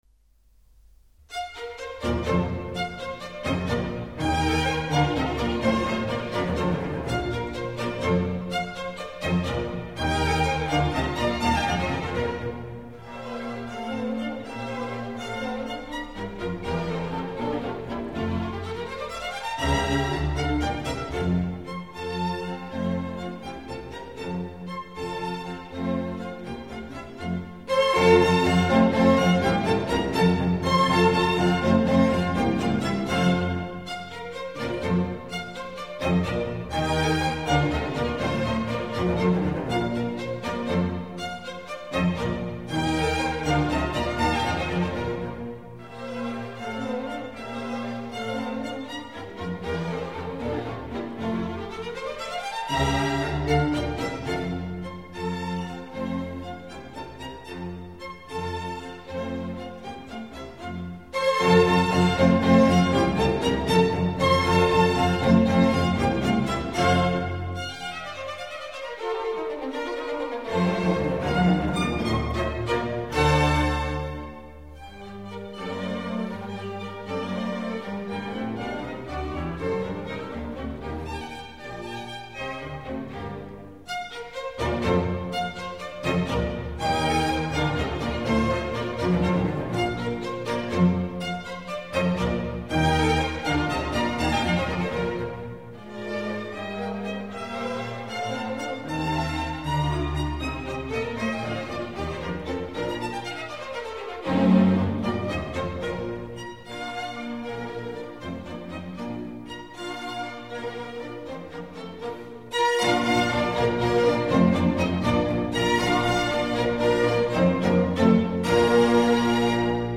Allegro assai